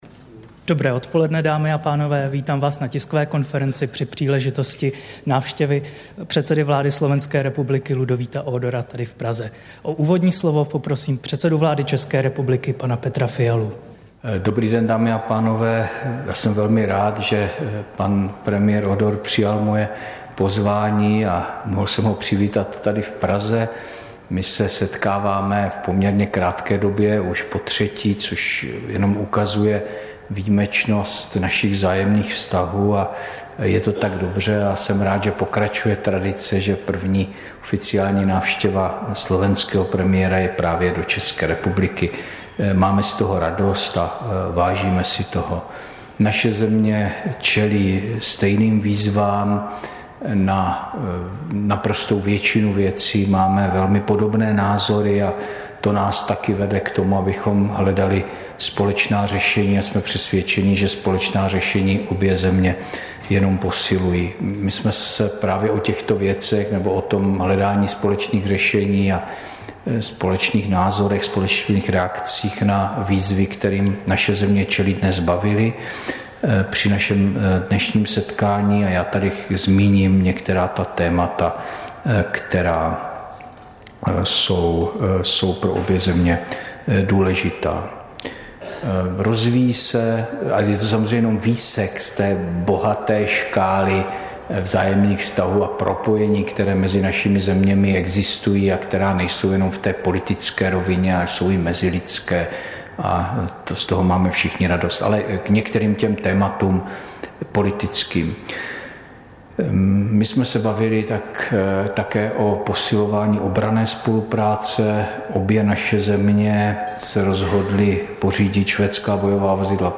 Tisková konference po jednání s předsedou vlády Slovenské republiky Ľudovítem Ódorem, 4. července 2023